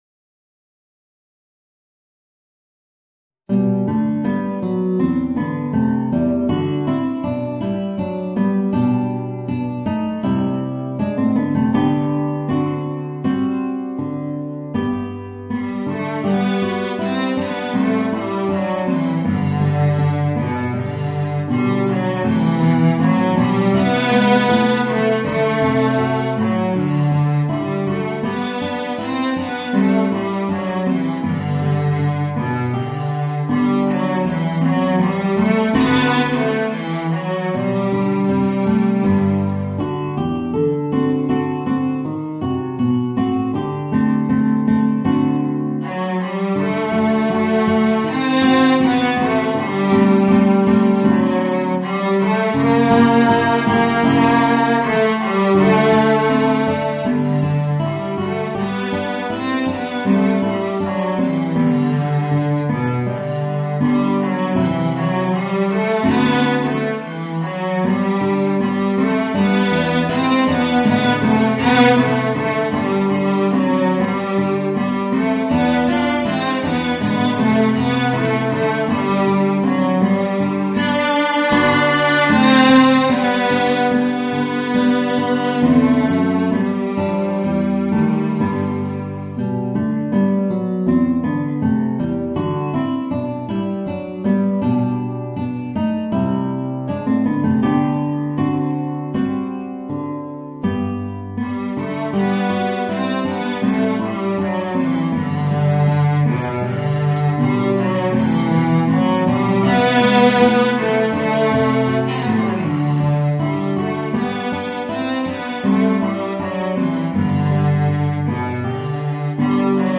Voicing: Guitar and Violoncello